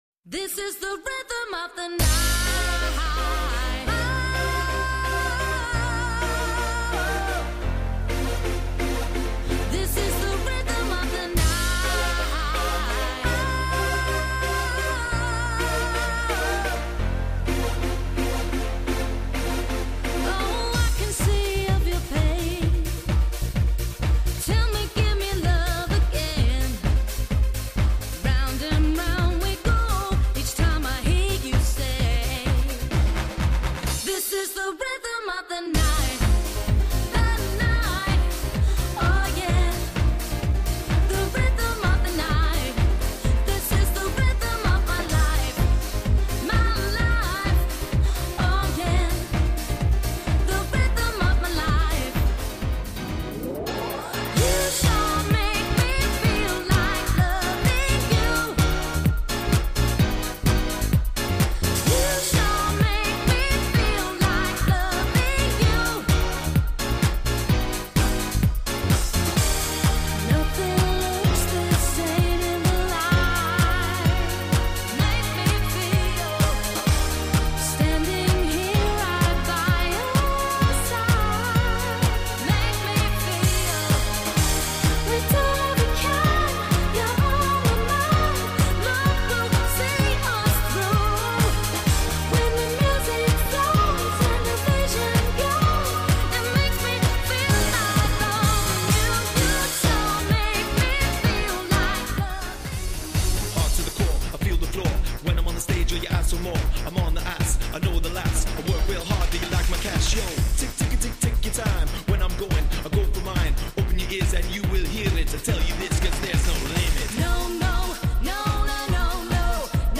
90s Dance Music